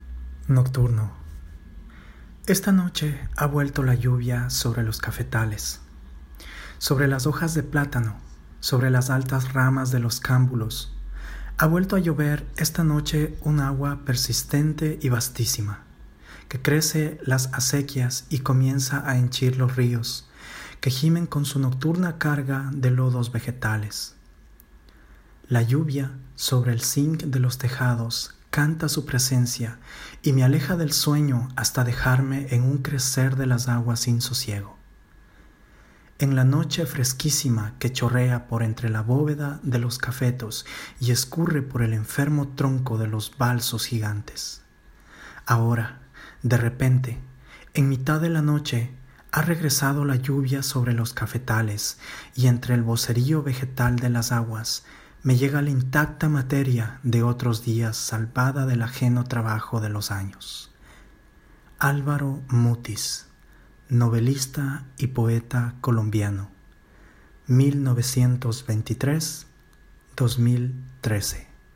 Spanisch, ruhige und angenehme Stimme
Sprechprobe: Sonstiges (Muttersprache):